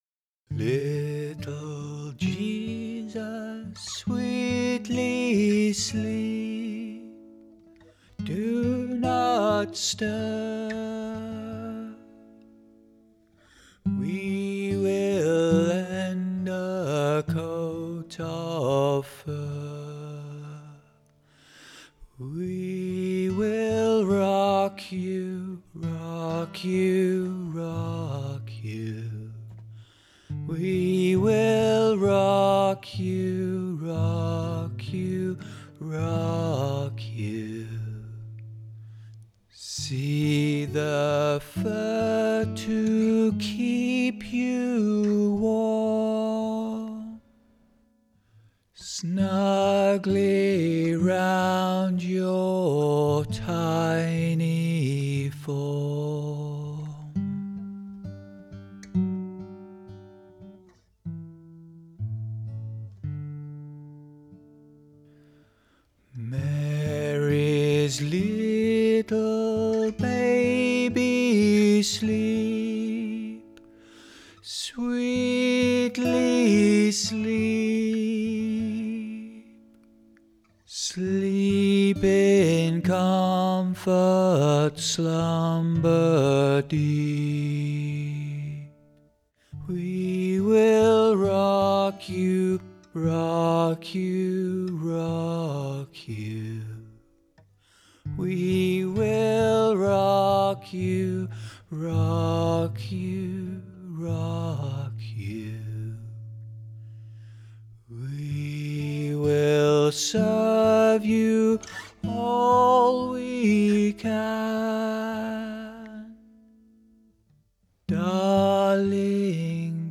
A Czech Christmas lullaby for the little man in the manger
Lullaby